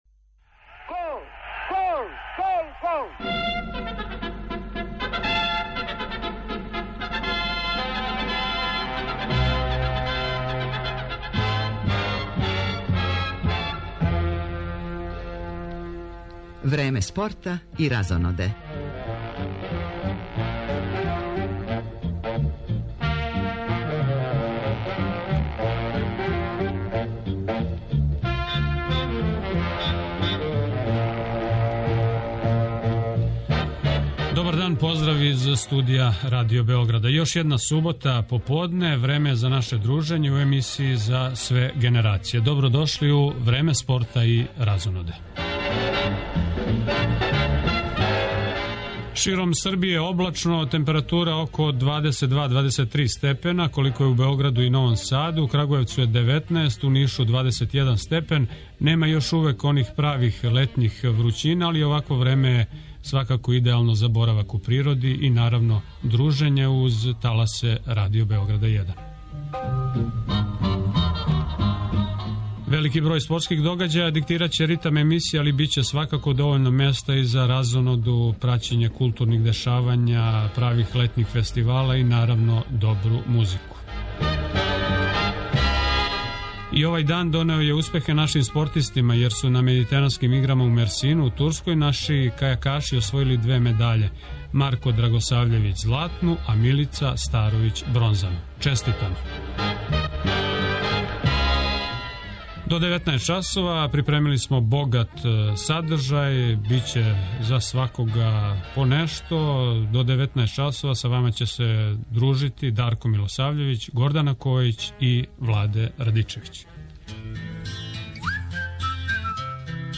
Наш репортер јављаће се из Хамбурга, одакле ћемо пратити утакмицу одбојкашица у Европској лиги.